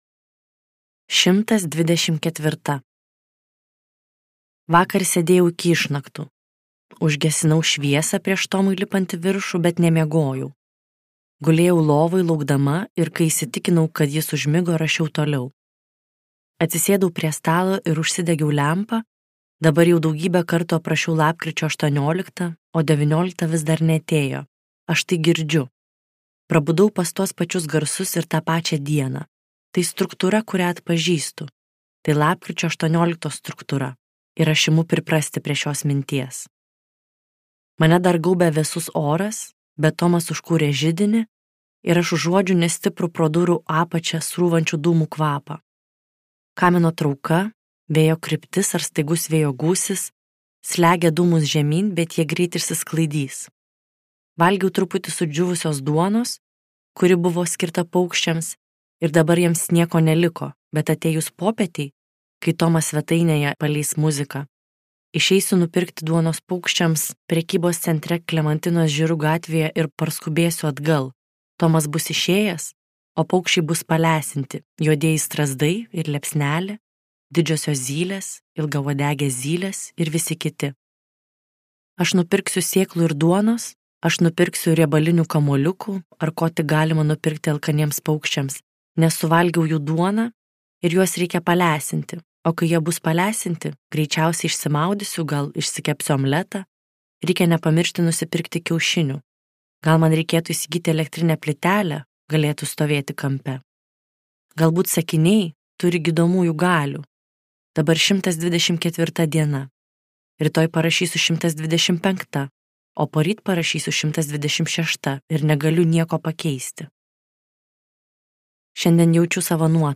Apie tūrio apskaičiavimą I | Audioknygos | baltos lankos